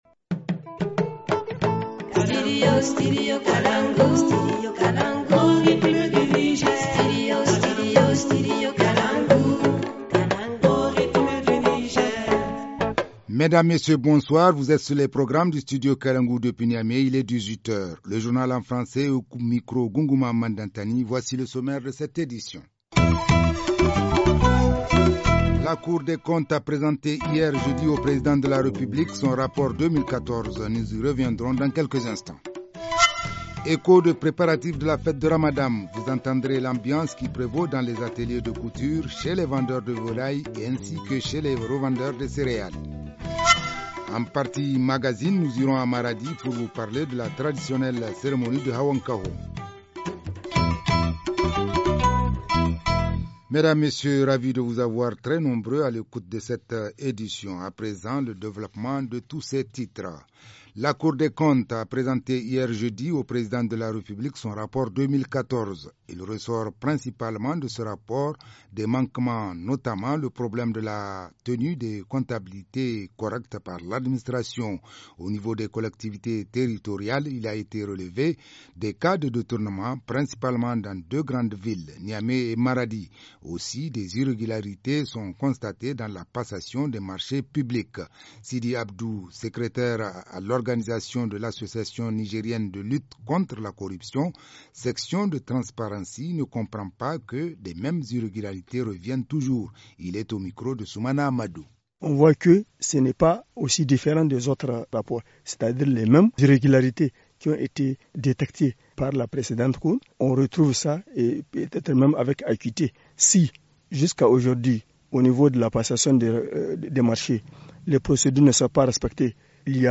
2. Echos de préparatifs de la fête de ramadan, vous entendrez l’ambiance qui prévaut dans les ateliers de couture, chez les vendeurs de volailles ainsi que chez les revendeurs des céréales.